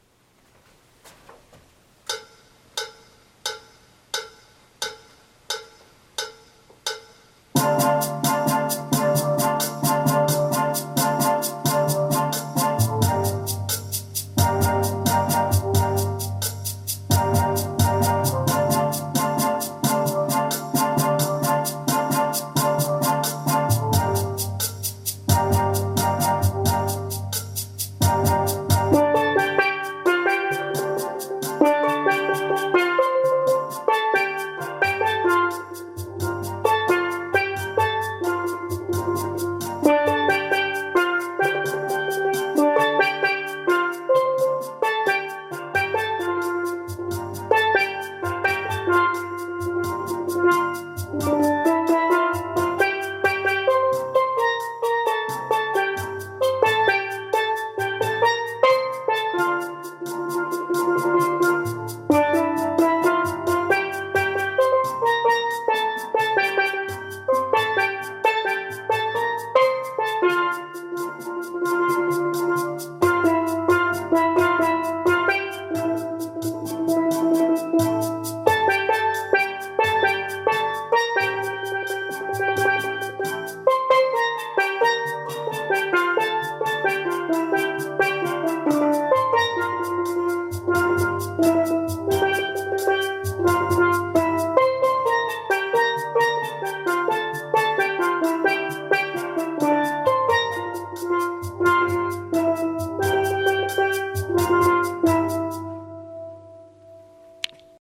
Ténor Maloya Vid .mp3